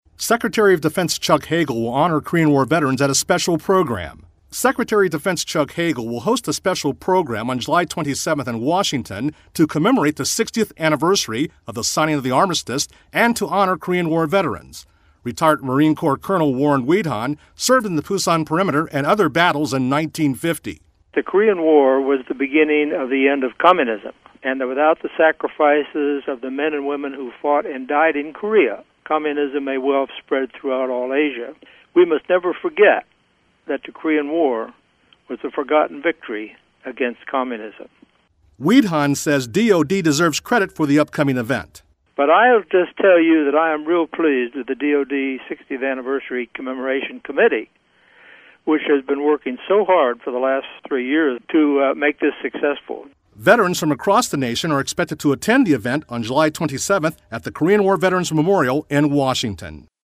July 10, 2013Posted in: Audio News Release